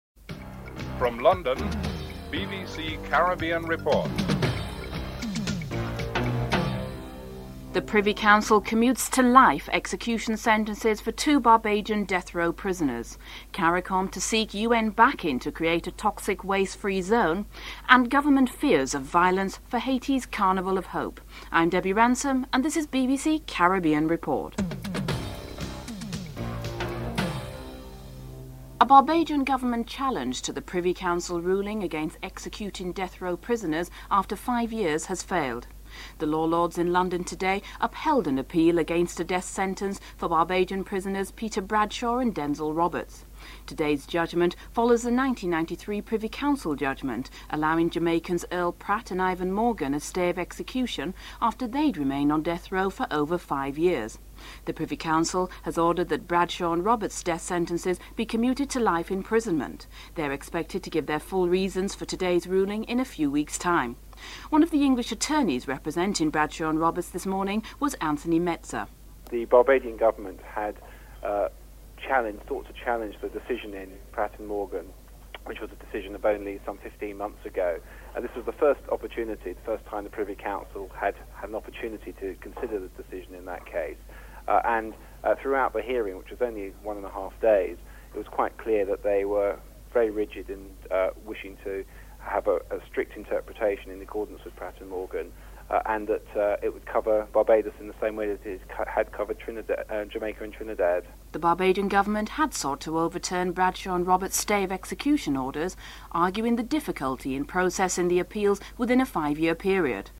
The Bahamas and Haiti have drawn up a framework agreement for the deportation of illegal Haitian refugees. Bahamas Governor General, Sir Orville Turnquest spoke about the rationale for deporting the Haitian refugees.